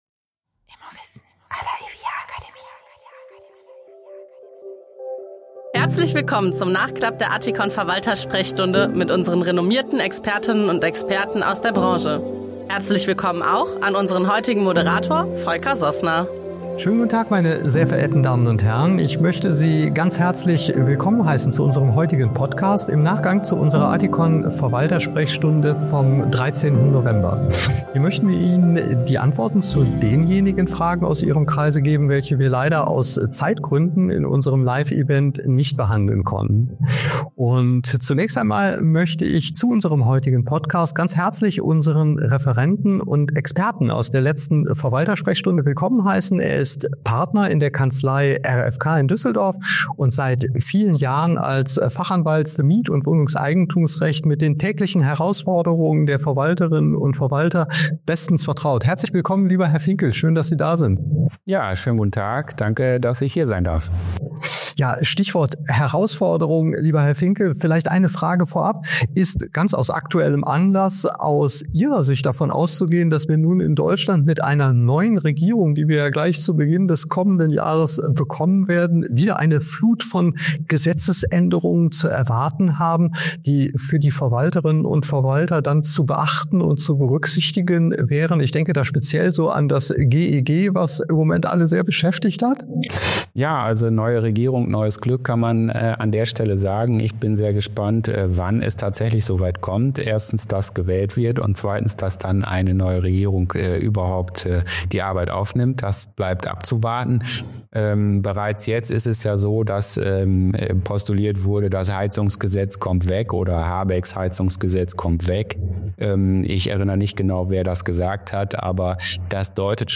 Expertenrunde dieser Folge: